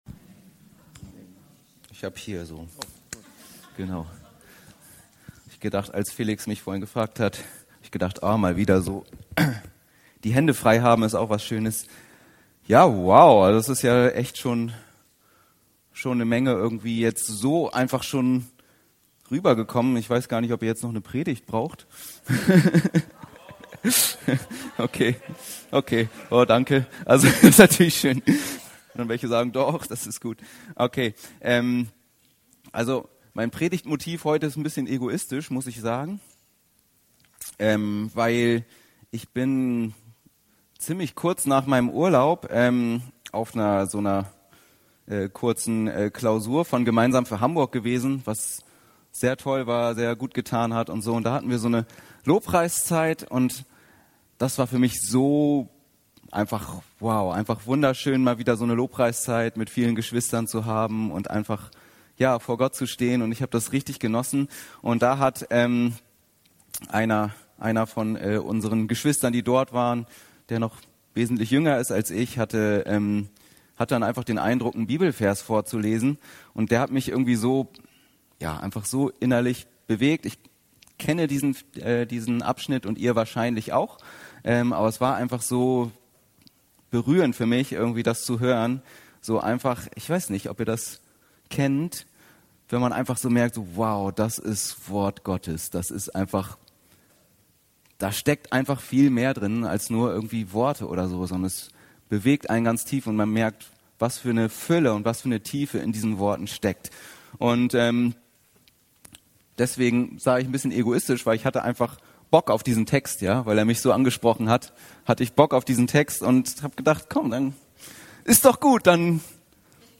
"Un-kaputtbar" - 2 Kor 4,6-12; 16-18 ~ Anskar-Kirche Hamburg- Predigten Podcast